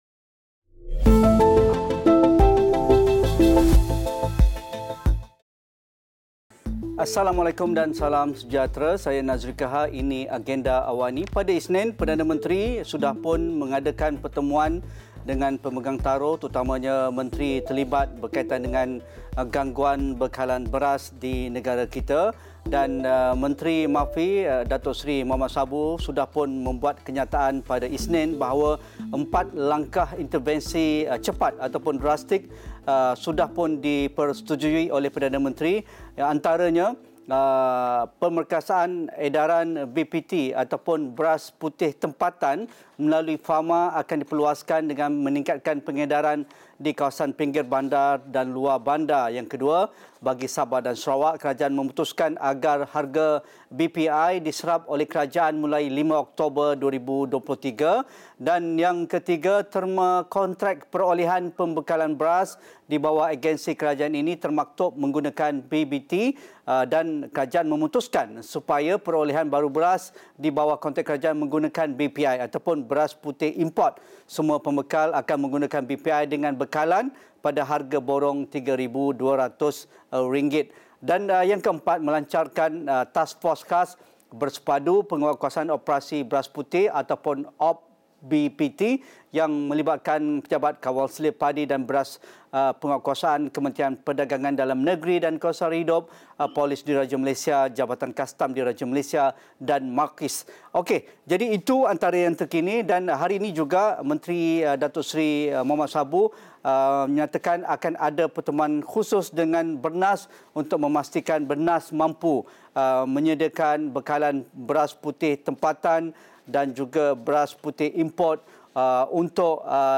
Sejauh mana langkah-langkah intervensi tambahan yang diumumkan kerajaan bagi menangani isu bekalan beras dalam negara ini mampu menstabilkan semula keadaan bekalan beras di negara ini? Diskusi 8.30 malam.